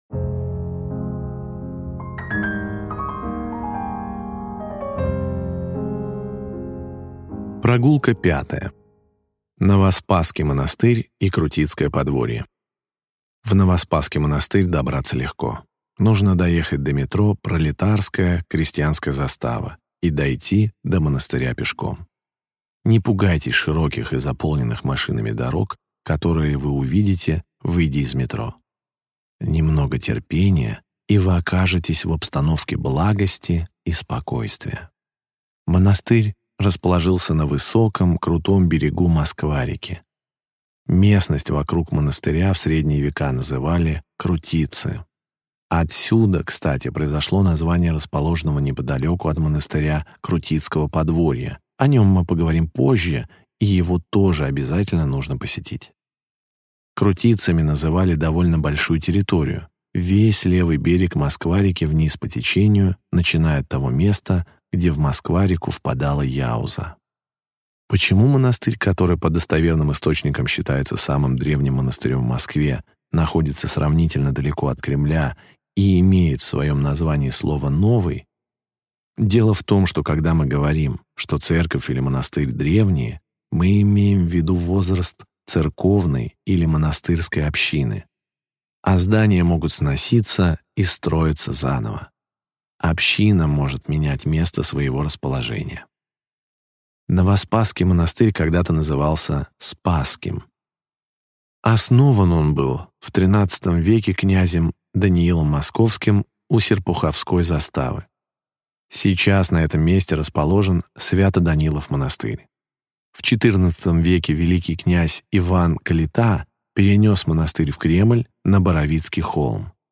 Аудиокнига Монастырский пояс Москвы. Глава 5. Новоспасский монастырь | Библиотека аудиокниг